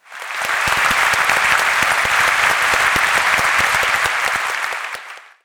applause-a.wav